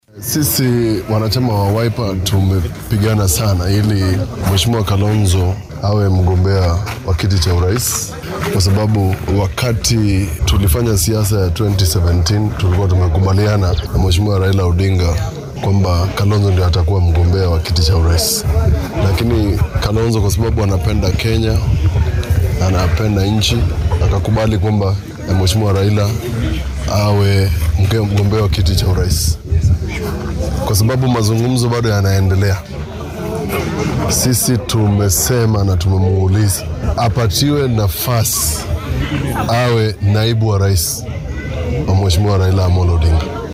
Qaar ka mid ah siyaasiyiinta xisbiga Wiper oo uu hor kacaya senatarka ismaamulka Makueni Mutula Kilonzo Junior ayaa musharraxa madaxweyne ee isbeheysiga Azimio Raila Odinga ka dalbaday inuu ku xigeen ka dhigto Kalonzo Musyoka. Hoggaamiyaashan oo warbaahinta kula hadlay magaalada Wote ee Makueni ayaa doonaya in madaxa ODM uu arrintan go’aan ka gaaro ka hor xilliga codeynta.